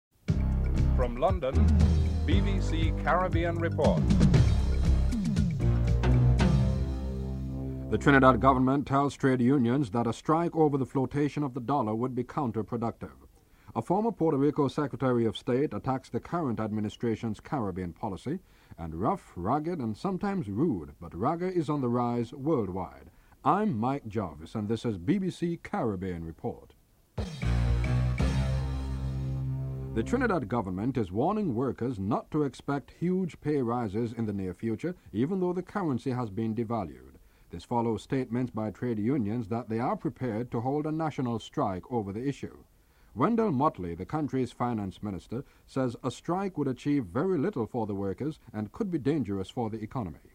Jamaican Dancehall artiste Shabba Ranks (born Rexton Rawlston Fernando Gordon ) also explains and defends the new music trend.